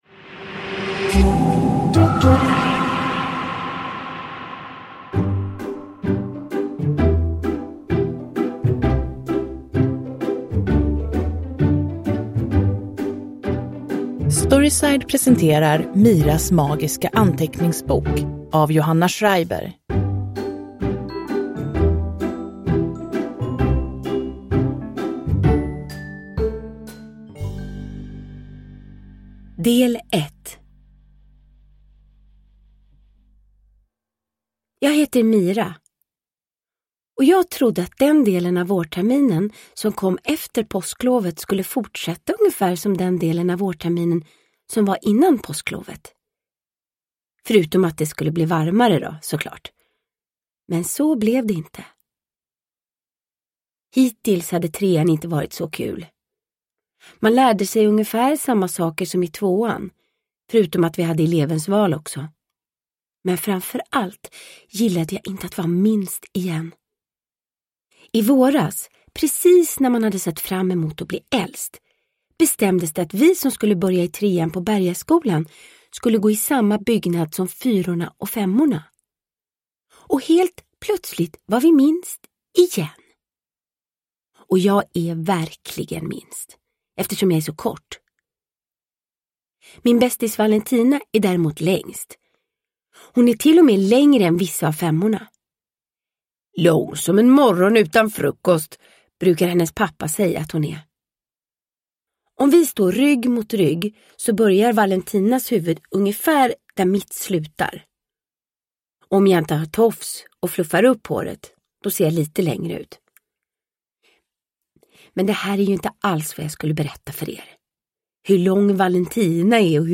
Miras magiska anteckningsbok – Ljudbok – Laddas ner
Uppläsare: Vanna Rosenberg